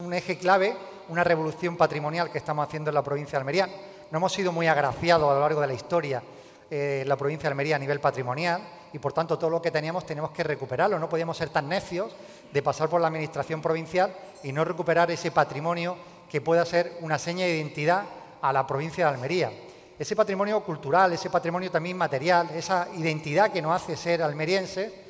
El presidente de la Diputación de Almería, Javier A. García, ha participado hoy en el foro ‘Andalucía desde sus Territorios’ que ha organizado la agencia de noticias Europa Press, celebrado en la Fundación Cajasol de Sevilla, para exponer la gestión y los proyectos de futuro de la provincia.